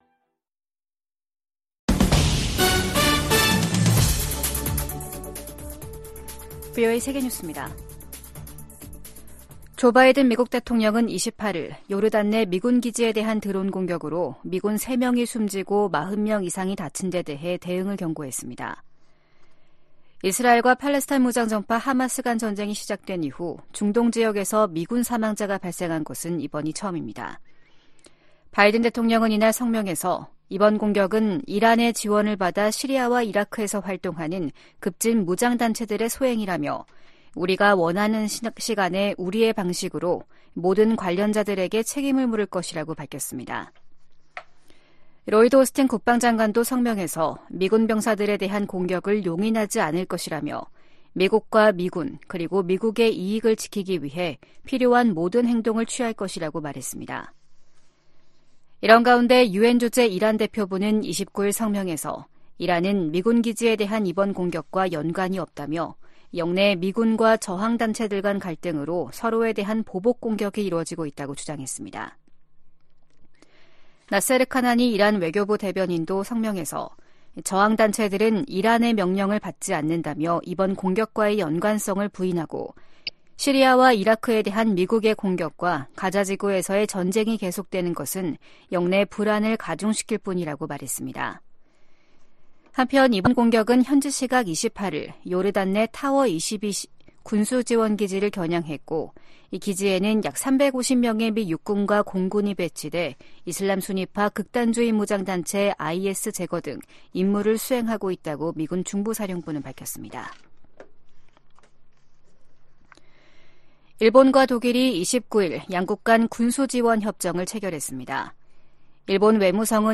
VOA 한국어 아침 뉴스 프로그램 '워싱턴 뉴스 광장' 2024년 1월 30일 방송입니다. 북한은 어제 시험발사한 미사일이 새로 개발한 잠수함발사 순항미사일이라고 밝혔습니다.